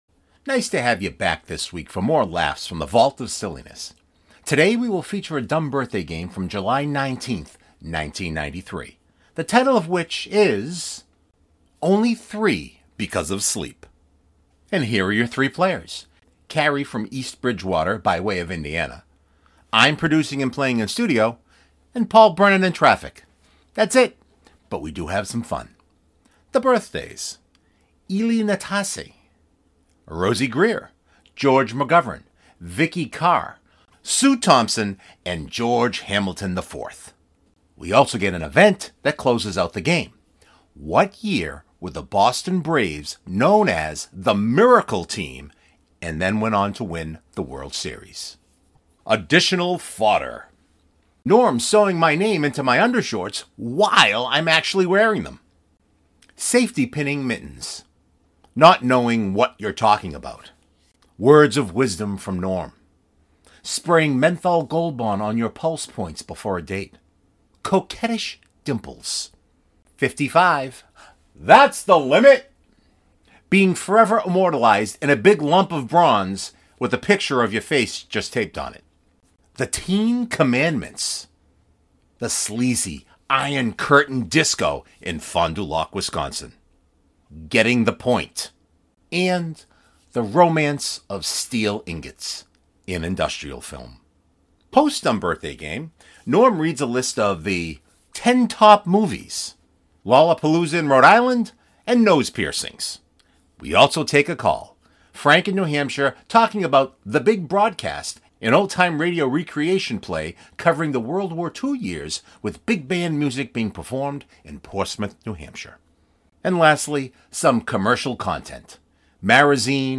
Lollapalooza in RI Nose piercings And we take a call!